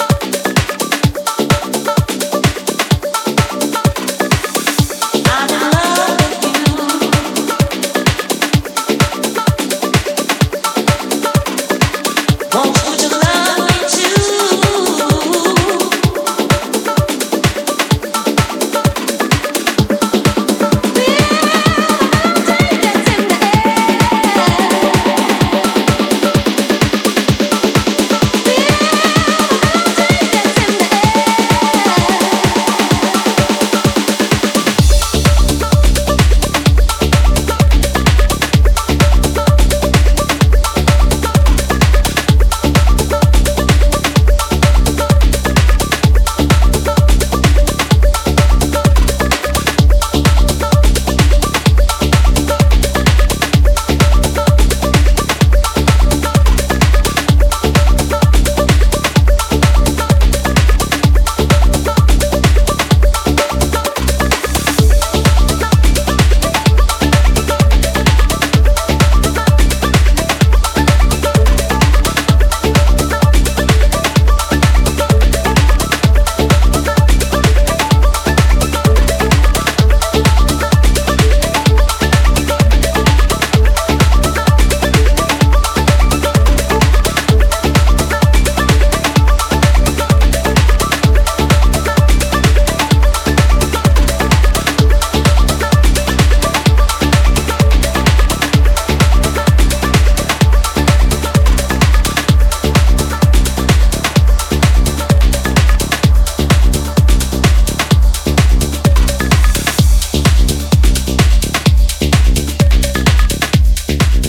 five tracks across house, electro and breaks
direct, fast and warm at the bottom end.